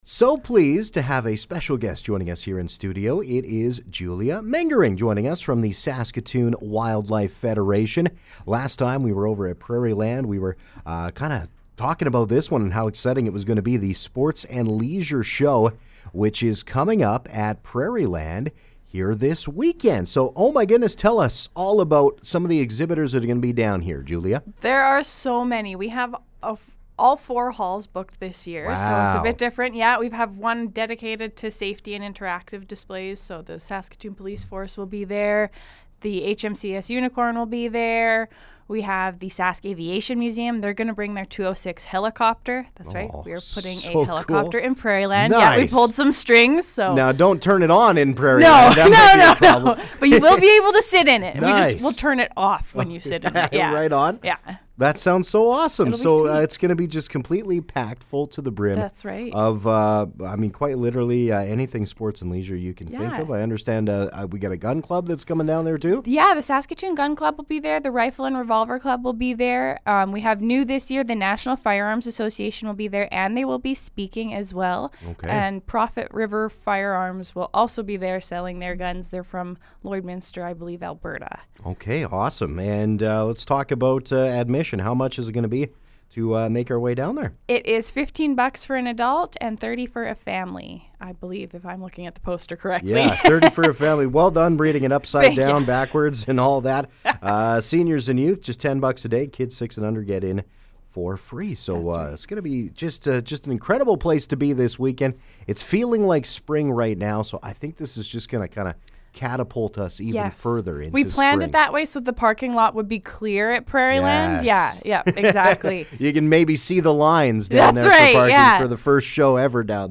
Interview: Saskatoon Sport and Leisure Show